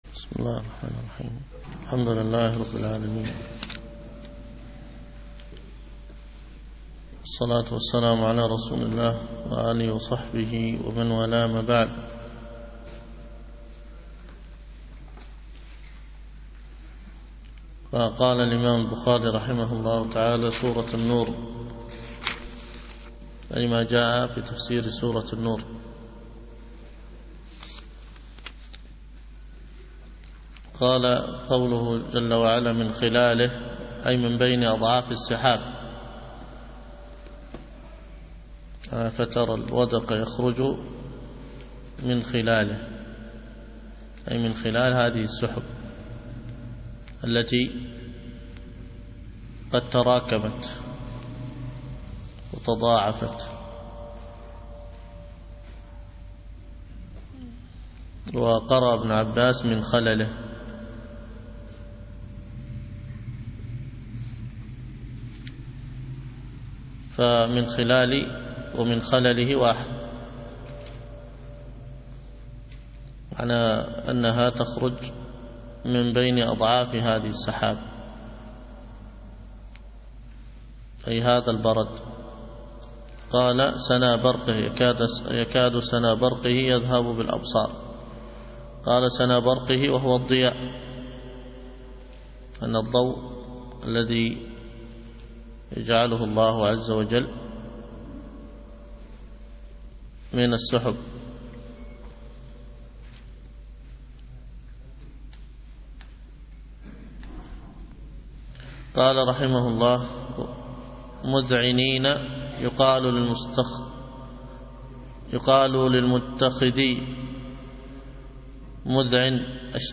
الدرس في كتاب التفسير من صحيح البخاري 71